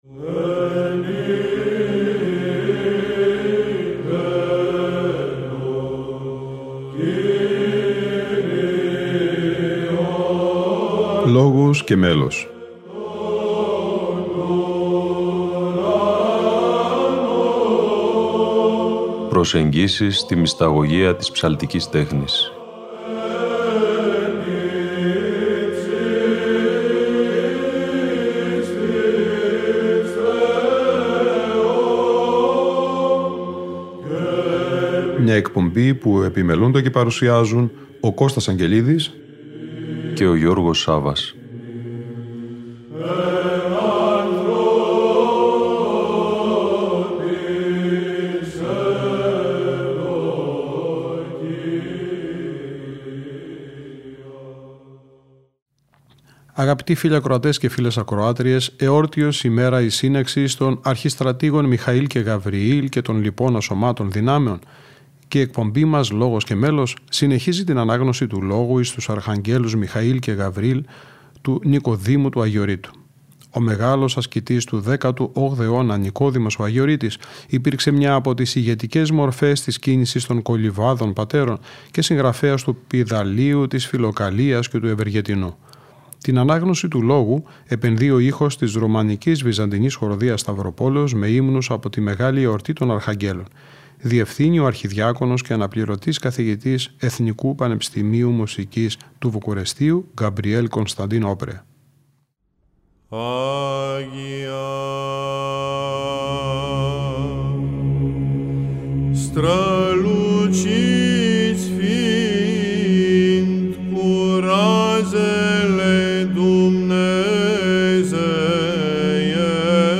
ΛΟΓΟΣ ΚΑΙ ΜΕΛΟΣ Λόγος εις τους Αρχαγγέλους Νικοδήμου Αγιορείτου - Μέλος Β.Χ. Σταυρουπόλεως (Β΄)